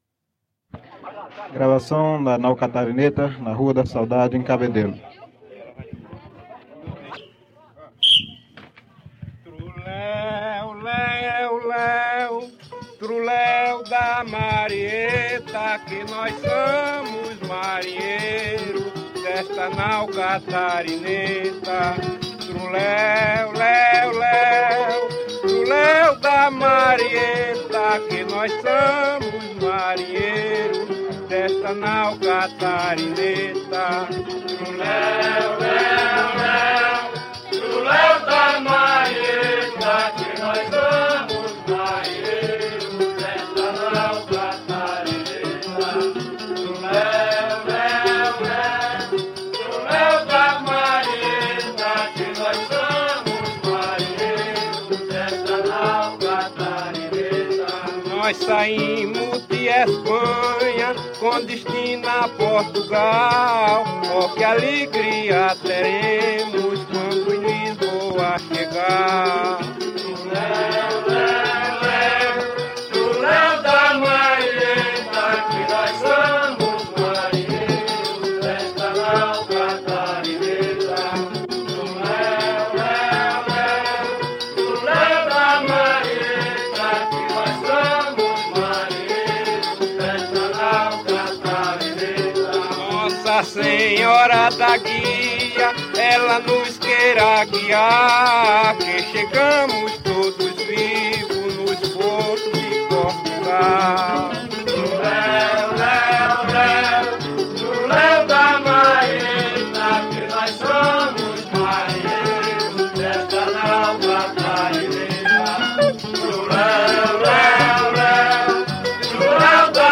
Série Brincantes - Autos e Danças
Apresentação da Barca na Rua da Saudade
A vida do marinheiro e Amassar as uvas (valsa).